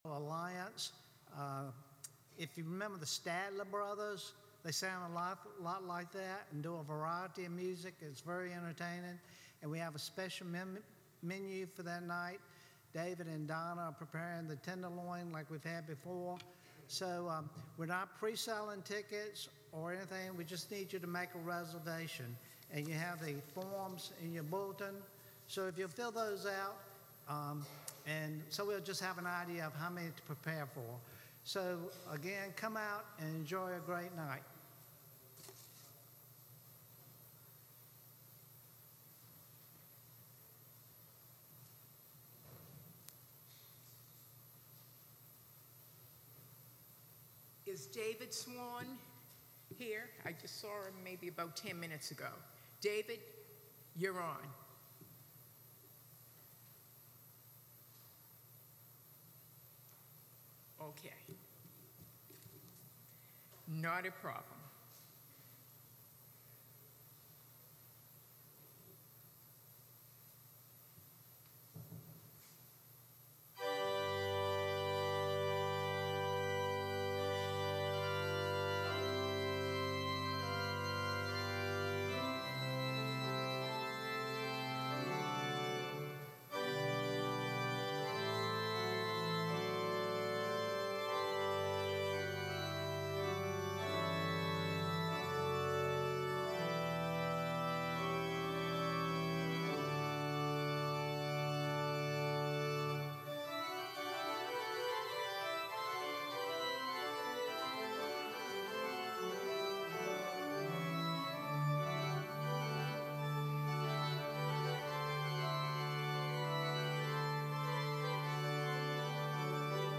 Sermons Who’s The Greatest?